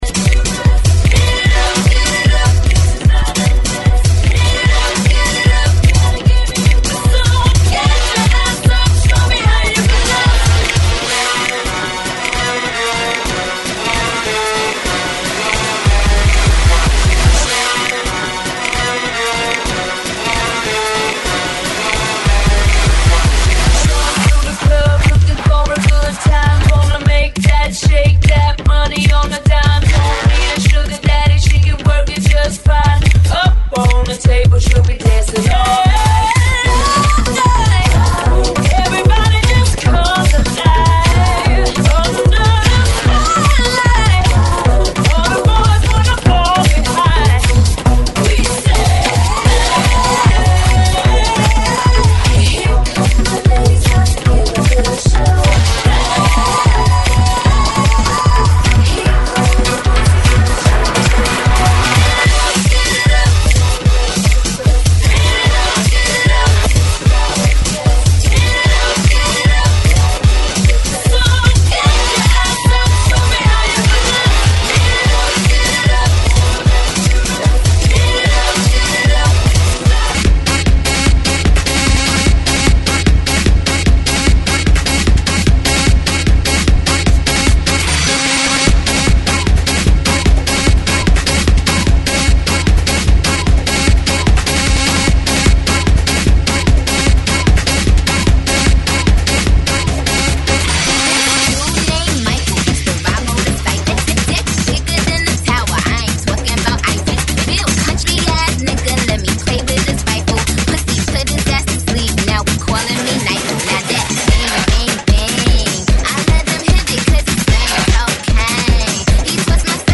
GENERO: POP INGLES
AEROBICS (STEP-HILOW)